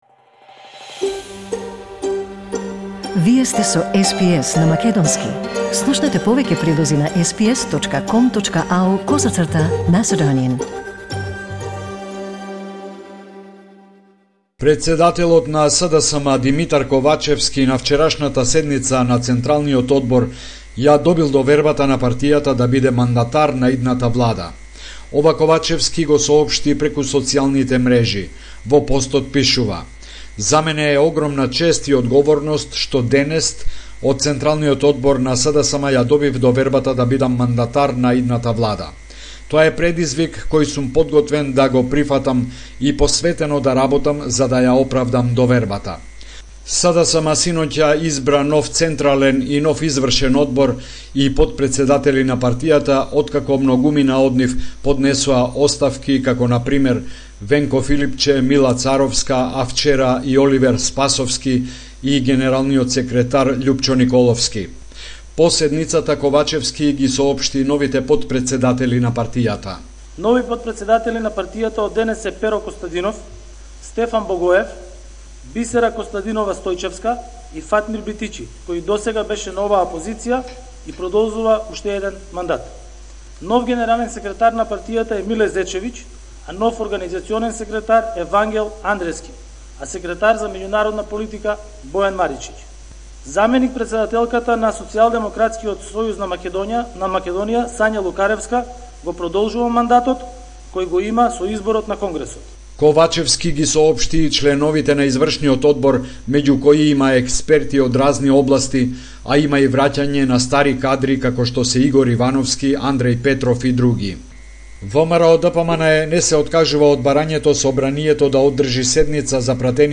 Homeland Report in Macedonian 28 December 2021